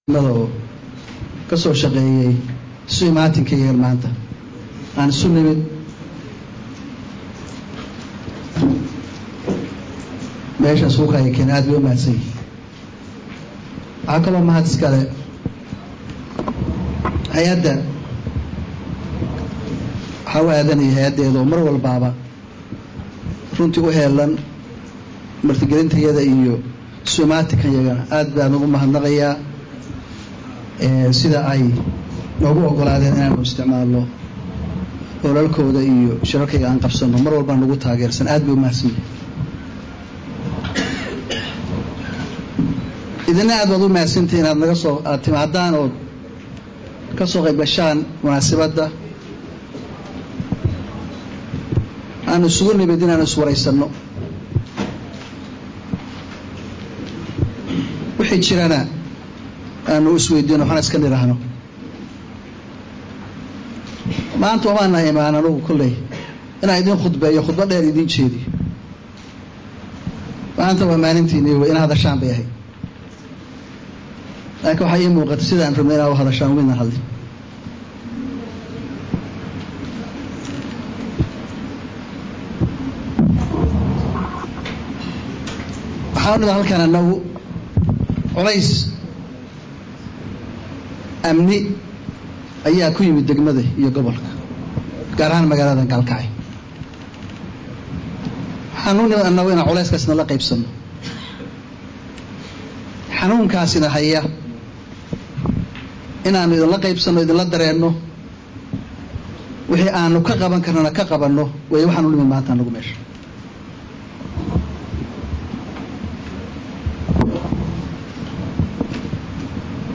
Dhagayso:Khudbad Xasaasi ah oo uu madaxweyne Gaas u jeediyey reer Gaalkacyo